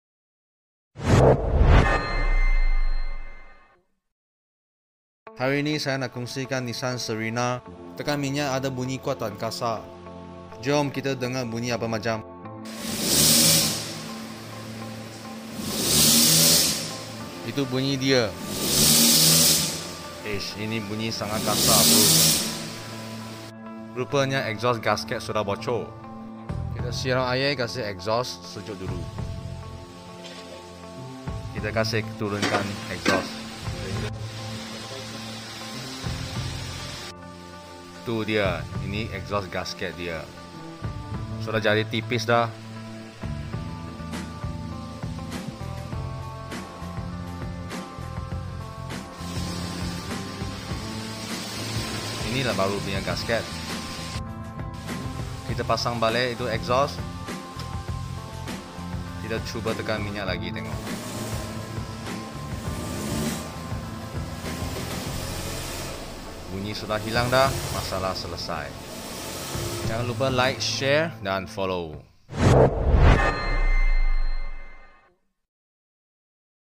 exhaust gasket bocor untuk Nissan sound effects free download